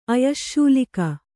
♪ ayaśśulika